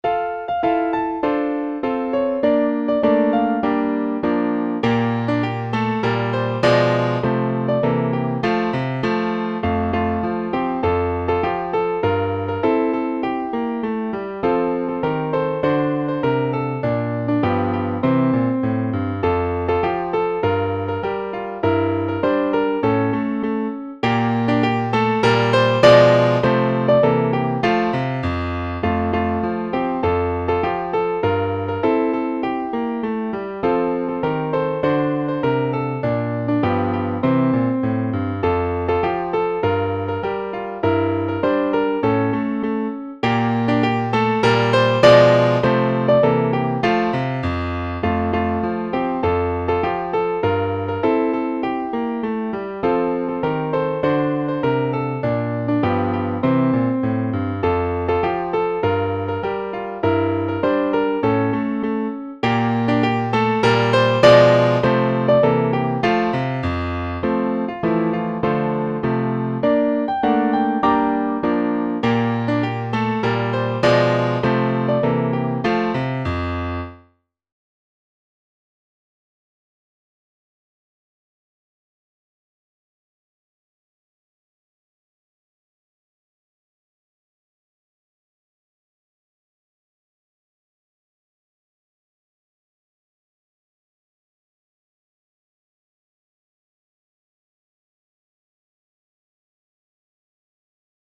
園歌のメロディーをダウンロードし、再生できます。
enka_gunma.mp3